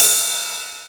43_03_ride.wav